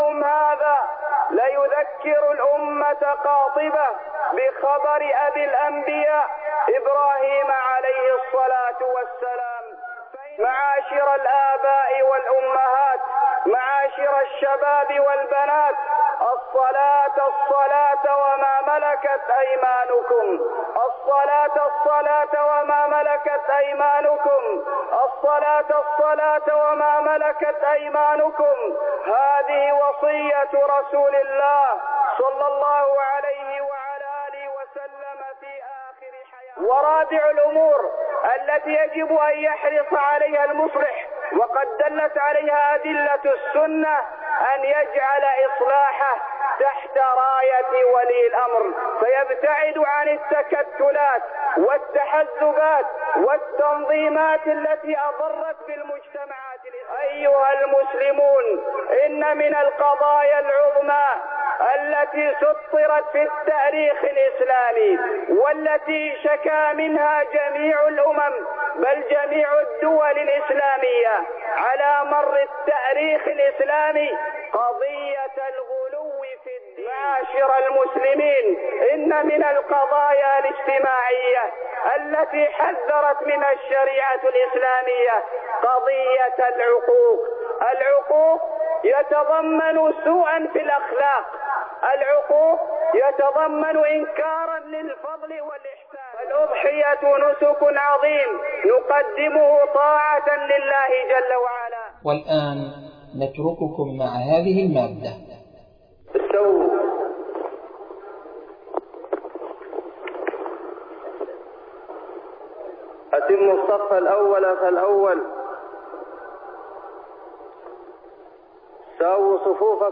خطبة عيد الأضحى 1426 هـ